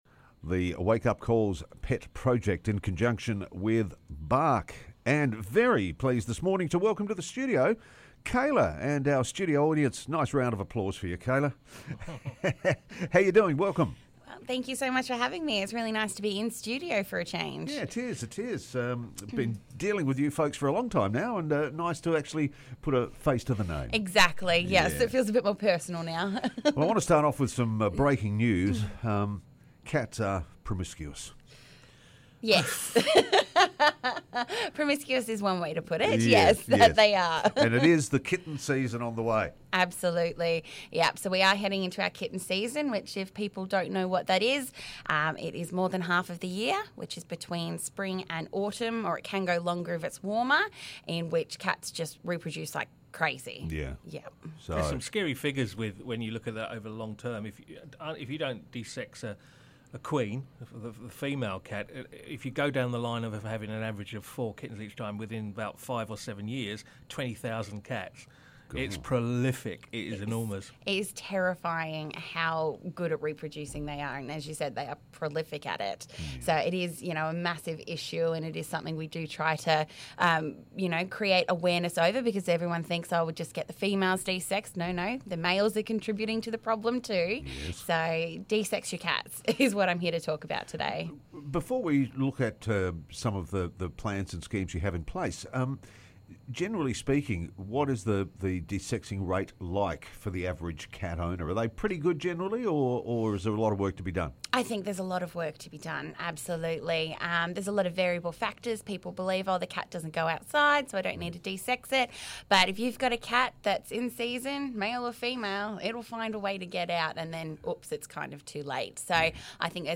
in Studio to chat about Kitten season and the need to get your cat desexed, and programs that can help and save you money...